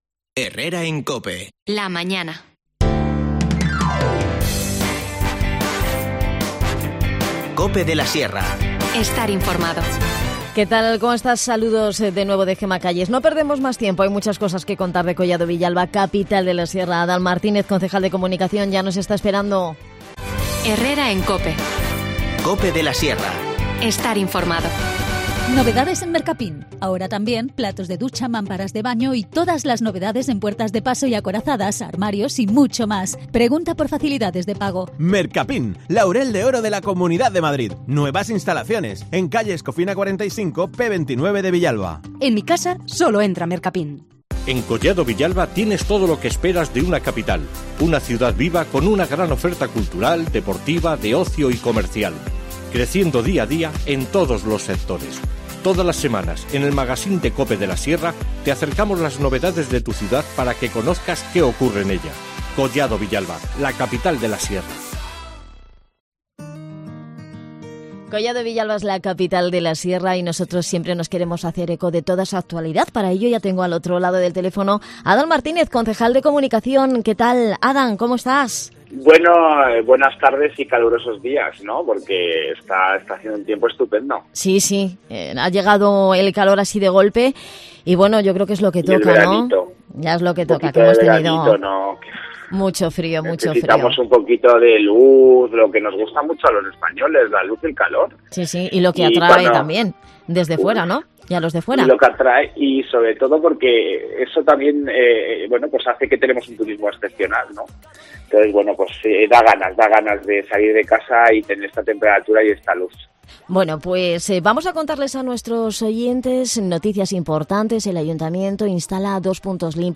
Redacción digital Madrid - Publicado el 10 jun 2021, 13:09 - Actualizado 17 mar 2023, 20:37 2 min lectura Descargar Facebook Twitter Whatsapp Telegram Enviar por email Copiar enlace Abordamos la actualidad de Collado Villalba, Capital de la Sierra, con Adan Martínez, concejal de Comunicación que pasa por la instalación de dos Puntos Limpios de Proximidad para facilitar el reciclado de residuos a los vecinos, nos recuerda que todavía quedan plazas libres en el Certificado de Profesionalidad de Atención Sociosanitaria. Además nos habla sobre el convenio de colaboración que han firmado con 'Bosques Sin Fronteras' para promocionar el cuidado y mejora del arbolado en la localidad, y la implicación de los ciudadanos en esta tarea.